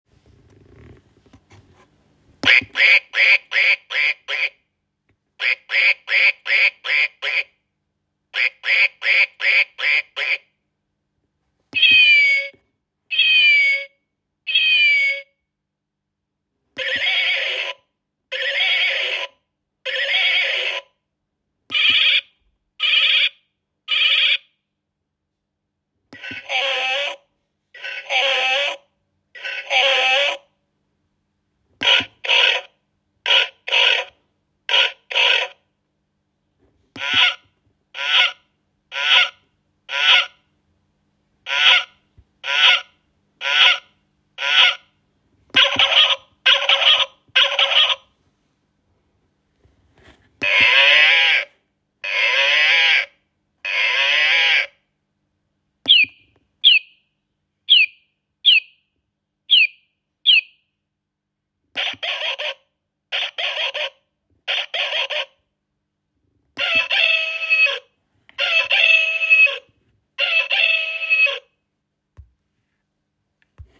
Nástenné hodiny so zvukom farmárskych zvierat
Každú hodinu nástenné hodiny vydávajú odlišný zvuk farmárskeho zvieraťa, sú preto vhodné aj pre deti.
Husa Krocan Kráva Kuře Pes Kohout Kachna Kočka Kůň Koza Osel Prase Nástenné hodiny majú 24 hodinový cyklus, ale nemusíte sa báť, že by vás hodiny v noci budili, v rozmedzí od 22:00 do 5:00 zvieratá žiadne zvuky nevydávajú .
nastenne-hodiny-se-zvuky-farmarskych-zvirat-E0133.m4a